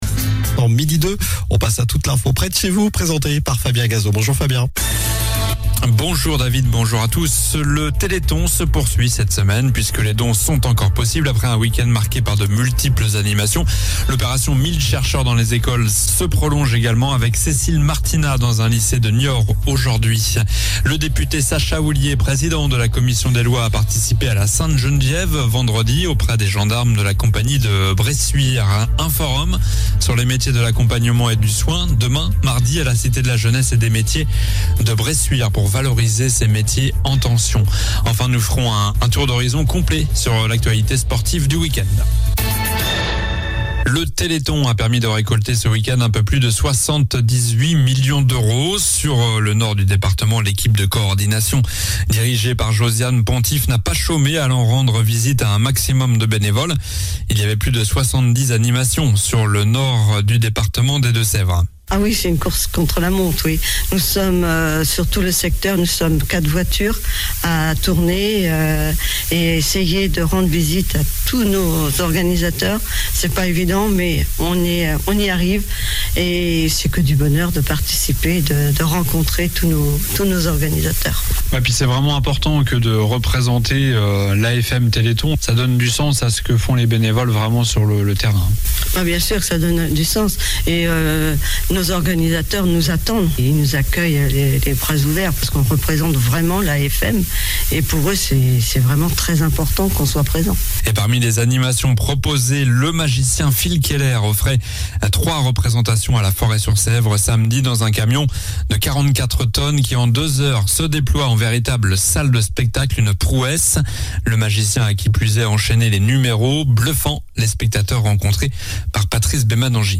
Journal du lundi 5 décembre (midi)